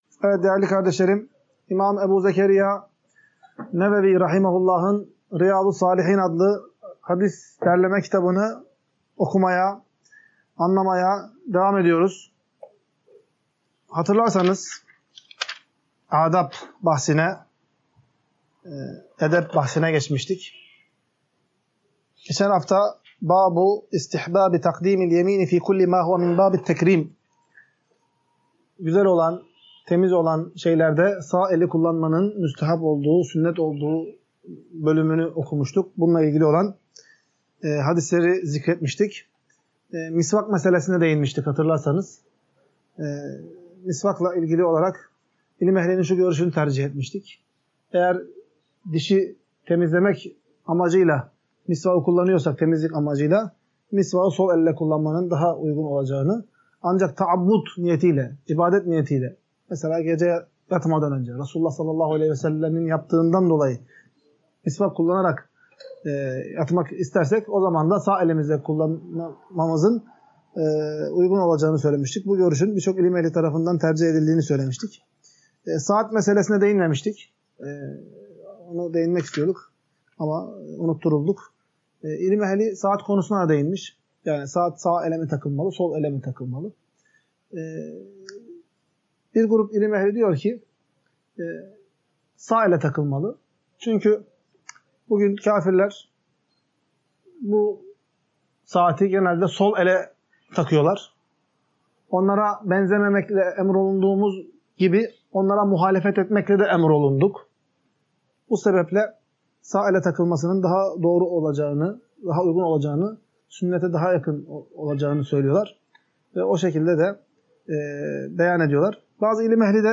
Ders - 1.